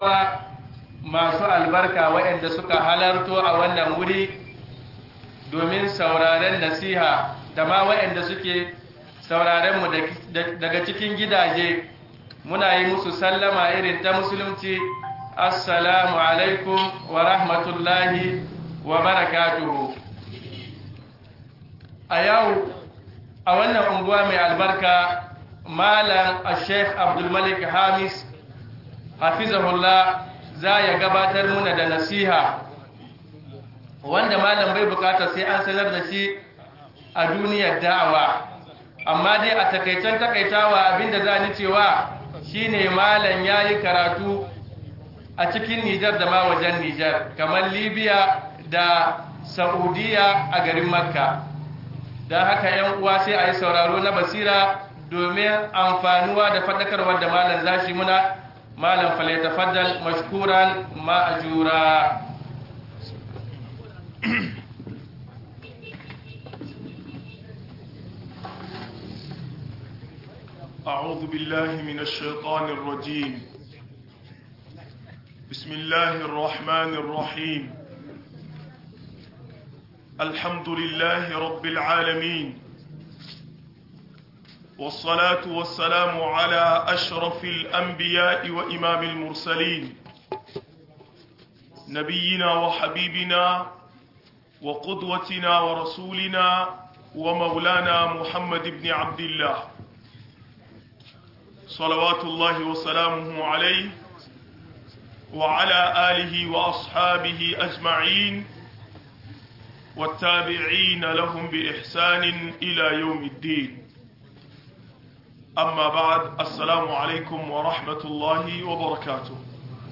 Ababen da ke tabbatar da ni'imomi - MUHADARA